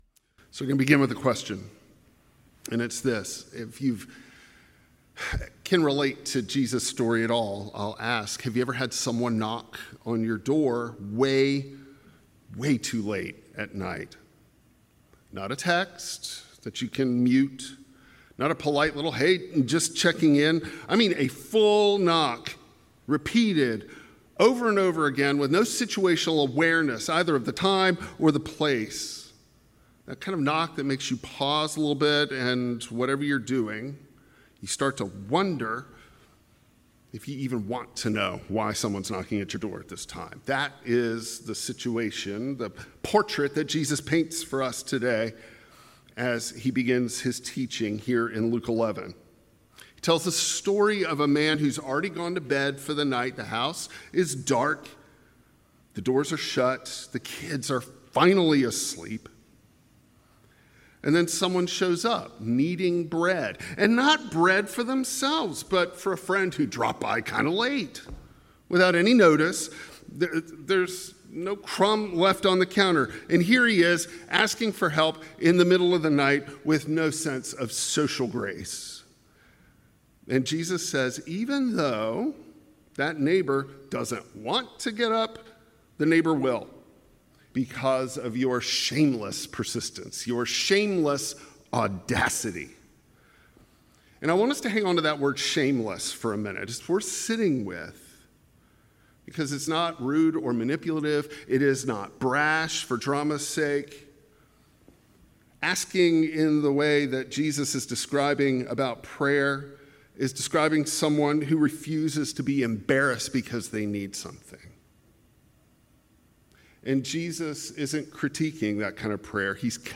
2025 Bold Enough to Ask Preacher